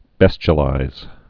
(bĕschə-līz, bēs-)